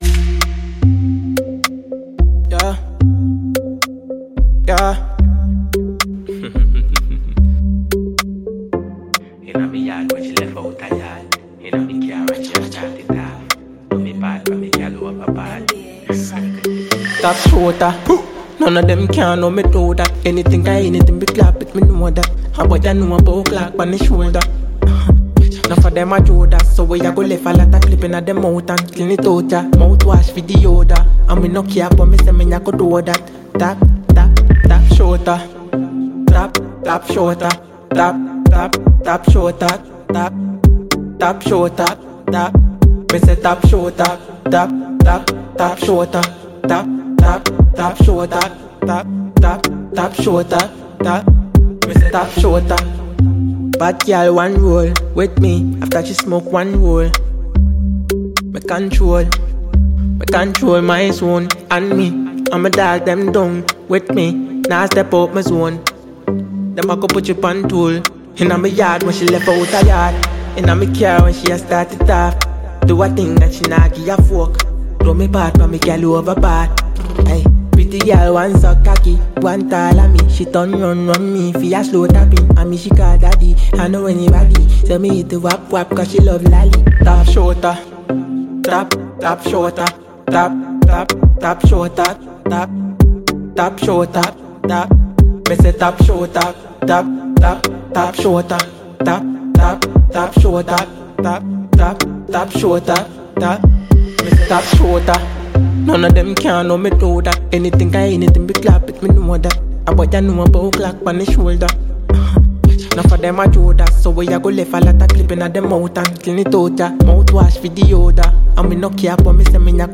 reggae dancehall